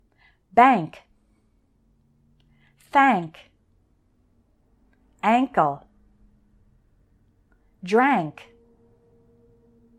How to pronounce DRANK and DRUNK
DRANK has an “ank” sound just like in the words bank, thank, and ankle.
Let’s practice – repeat after me: